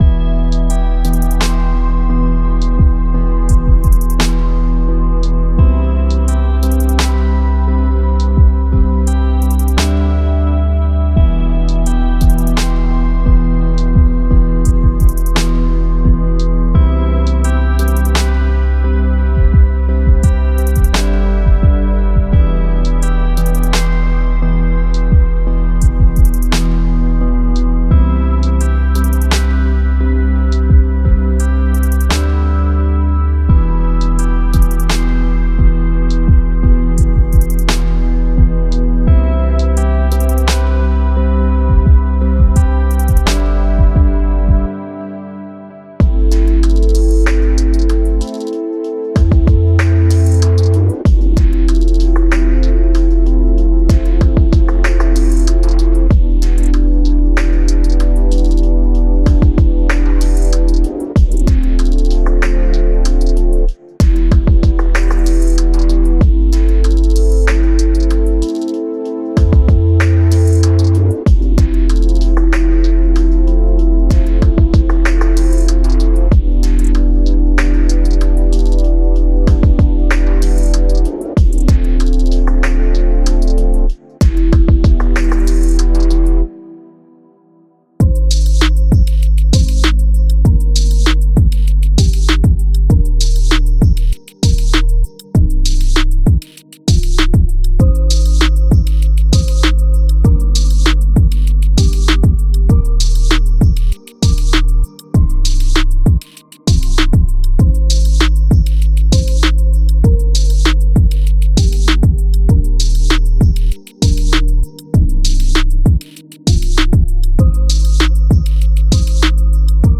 Ambient Chill Out / Lounge